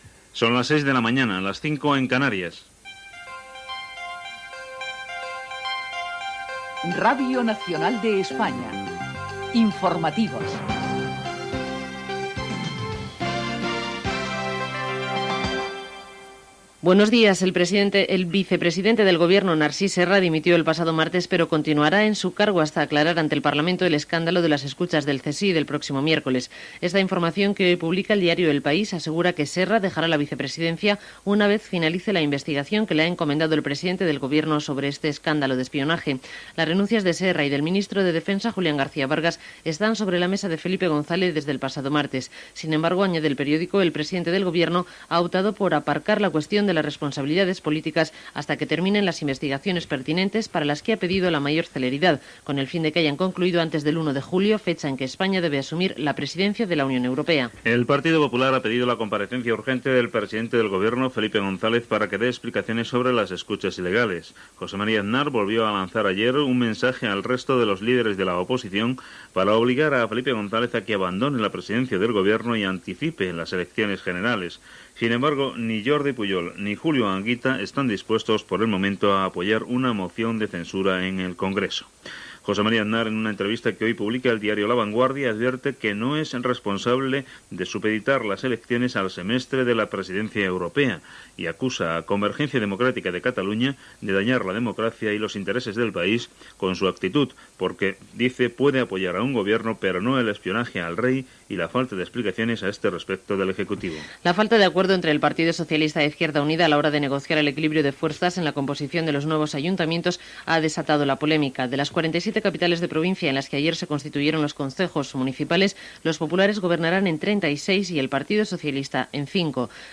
Hora, careta dels serveis informatius, dimissió del vicepresident Narcís Serra, constitució dels ajuntaments, Txetxènia, Xile.
Informatiu